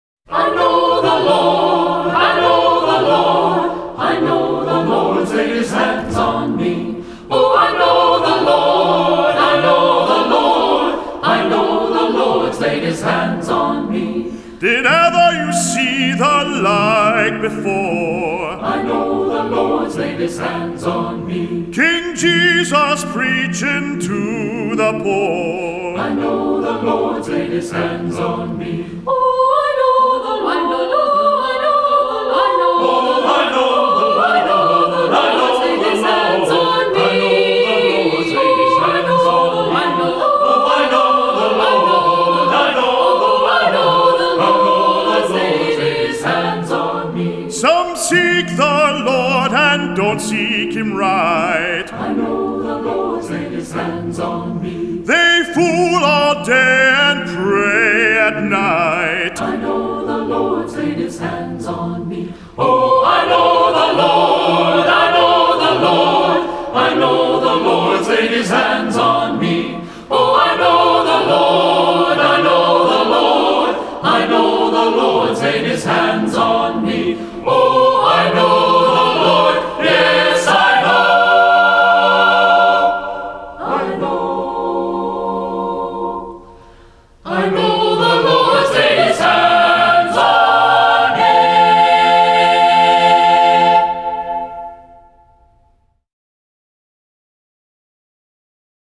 Voicing: SATB a cappella; tenor solo